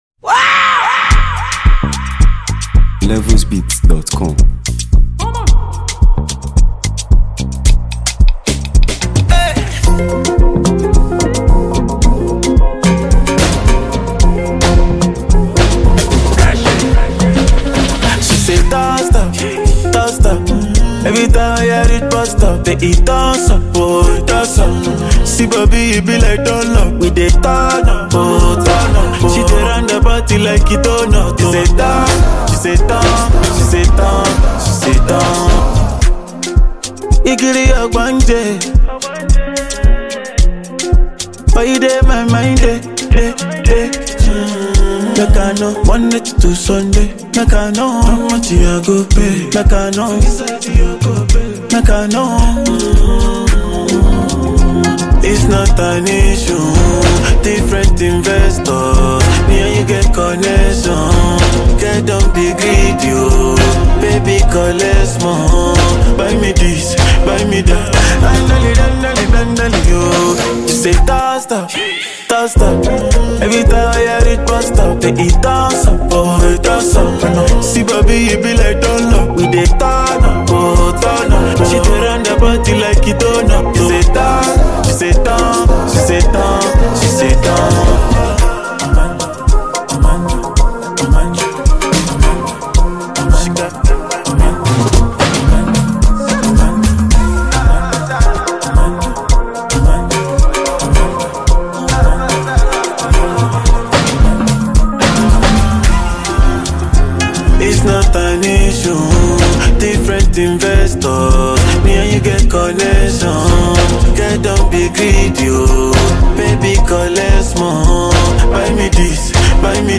vibrant and melodious track
infectious rhythms, smooth vocals, and top-tier production